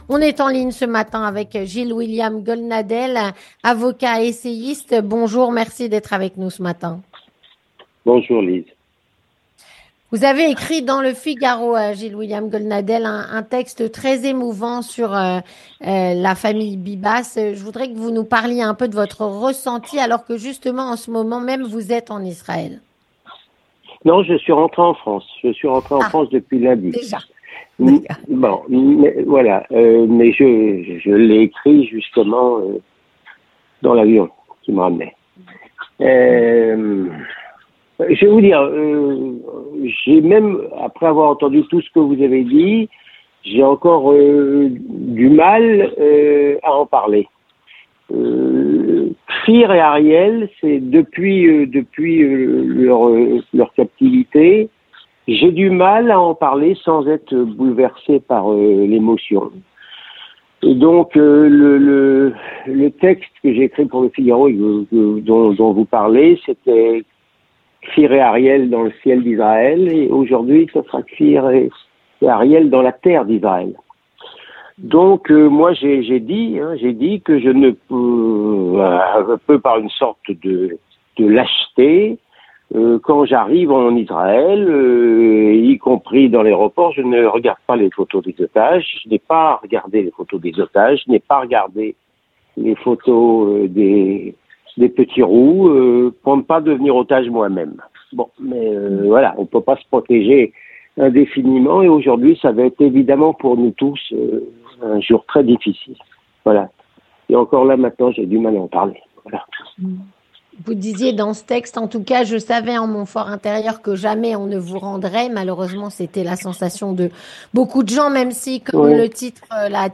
Avec Gilles-William Goldnadel, avocat, essayiste et auteur de "Journal de guerre" ainsi que de "Journal d’un prisonnier" parus tous les 2 aux éditions Fayard.